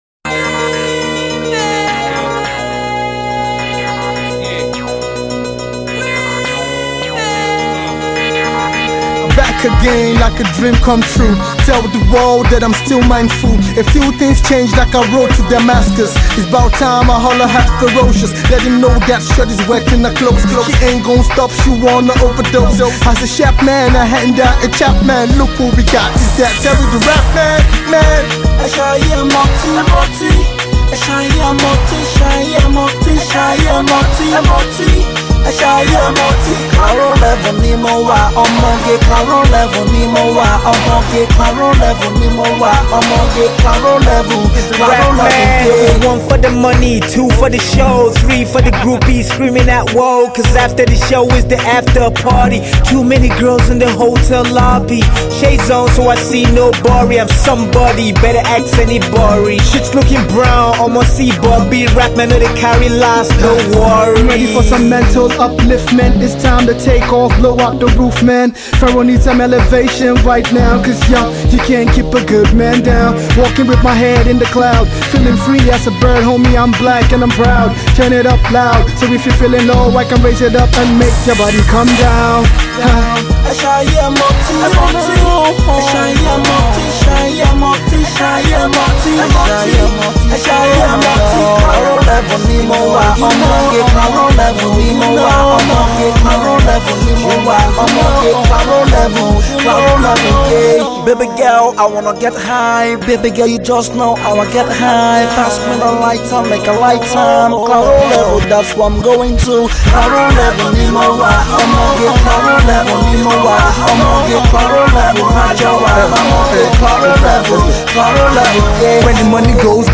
Nigerian Hip-Hop